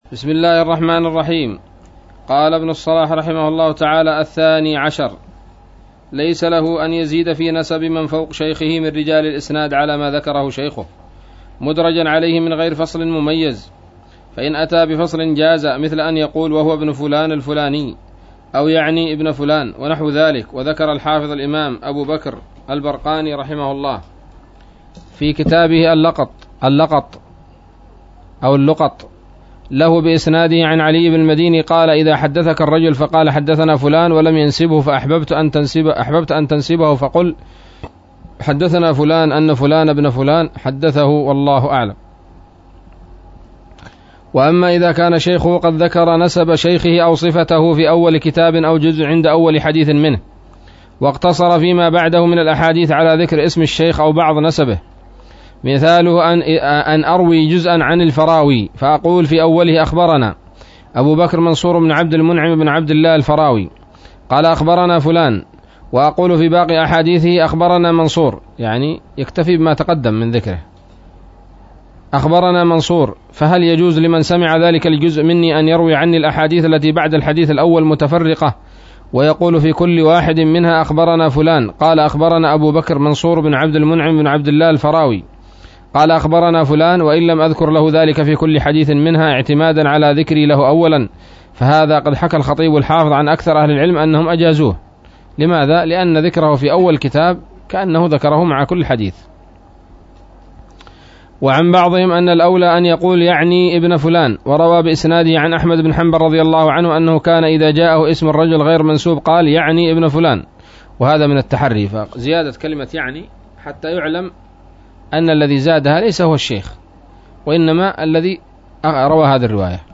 الدرس الثاني والثمانون من مقدمة ابن الصلاح رحمه الله تعالى